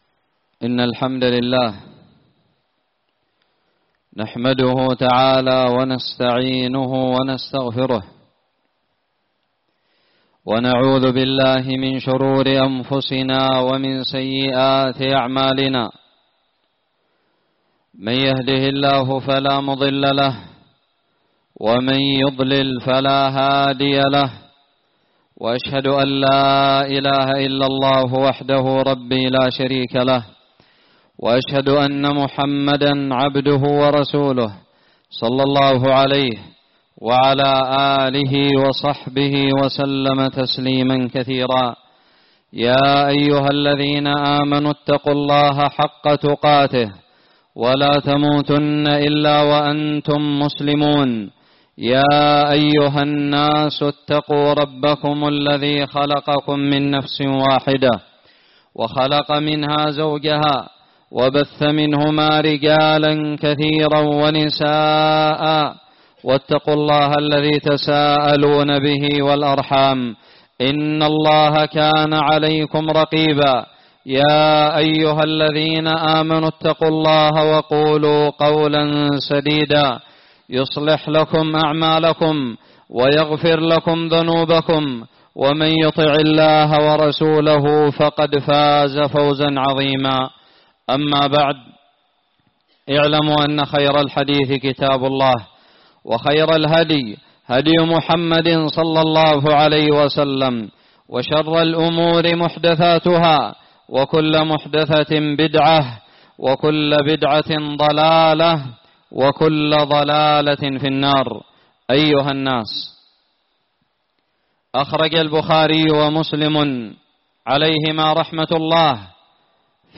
خطب الجمعة
ألقيت بدار الحديث السلفية للعلوم الشرعية بالضالع في عام 1440هــ